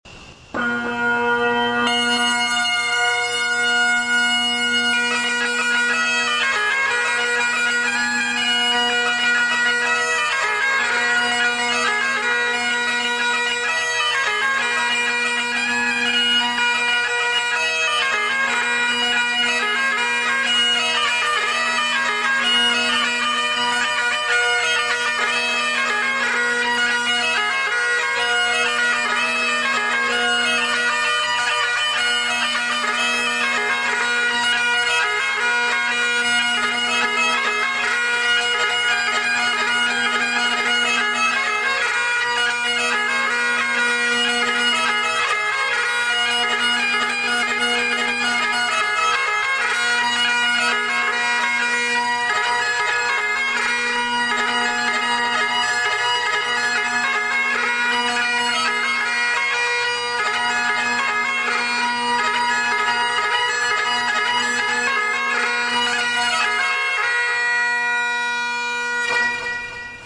This Baltimore Bagpiper has played bagpipes for Weddings, Funerals and Special Occasions, Gatherings and Events.
Baltimore-Bagpiper-1-BraesOfMelniesh.mp3